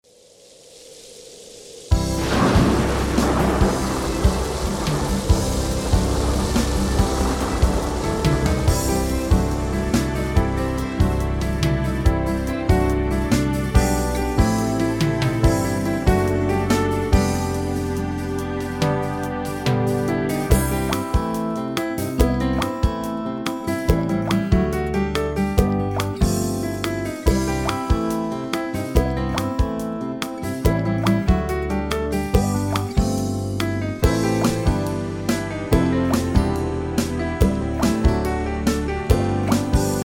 Listen to a sample of the instrumental version.
Downloadable Instrumental Track